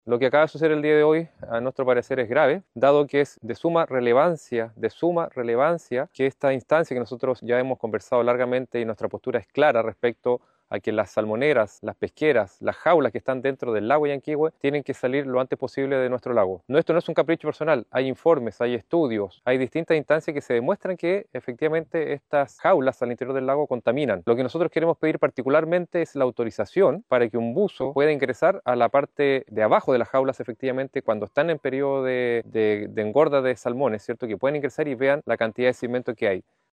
cuna-alcalde-frutillar.mp3